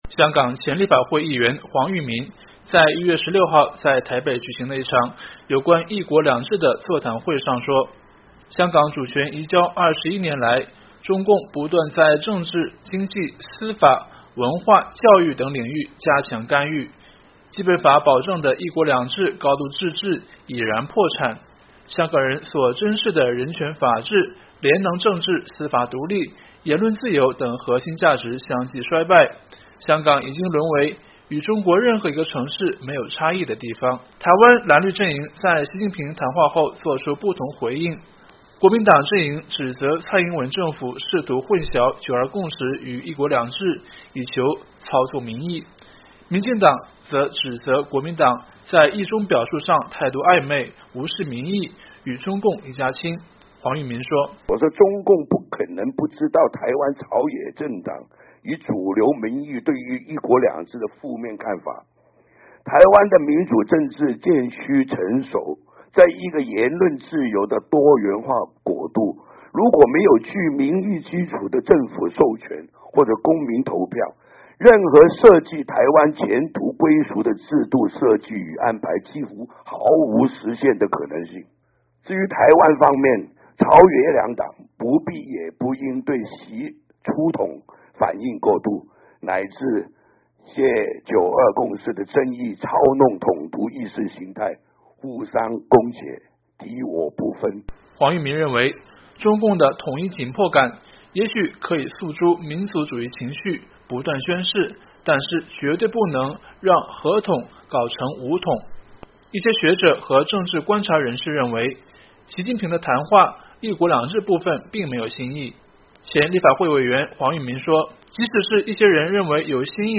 香港前立法会议员黄毓民在1月16日在台北举行的一场有关“一国两制”的座谈会上说，香港主权移交21年来，中共不断在政治、经济、司法、文化、教育等领域加强干预，《基本法》保证的“一国两制、高度自制”已然破产，香港人所珍视的人权法治、廉能政治、司法独立、言论自由等核心价值相继衰败。